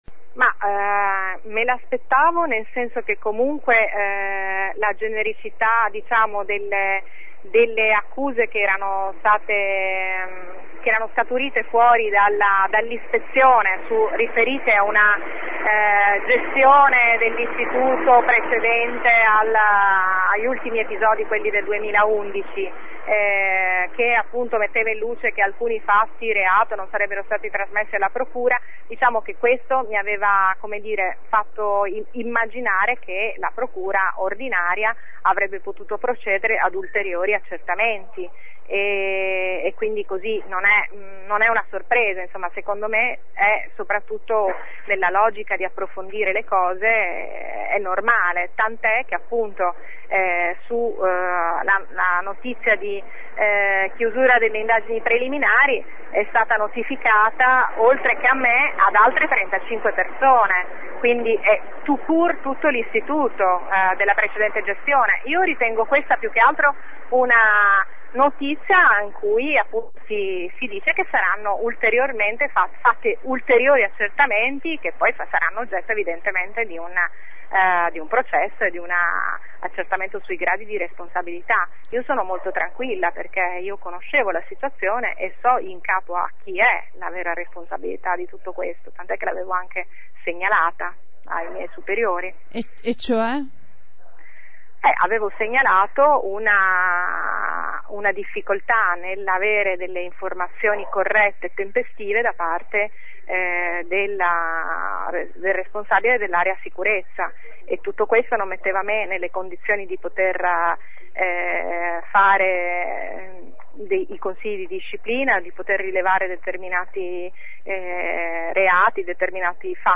Sentiamola ai nostri microfoni: